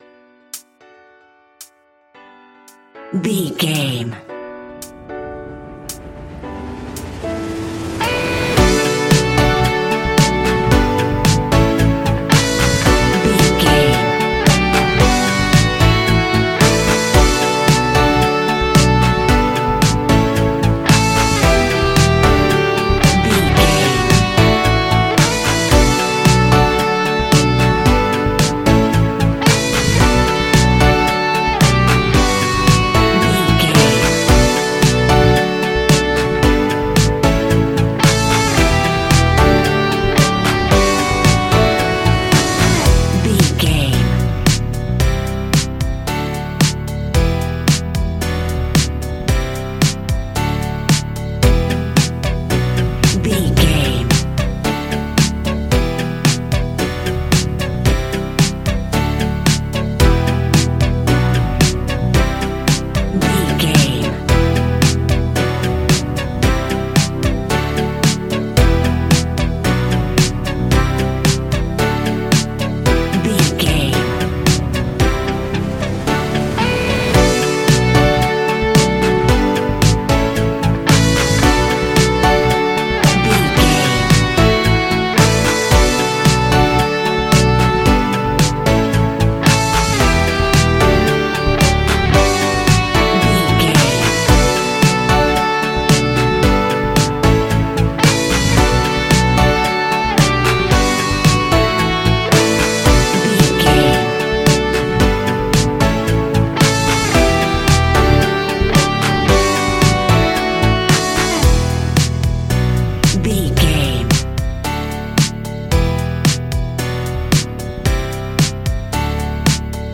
Ionian/Major
D♭
ambient
electronic
new age
chill out
downtempo
pads
space music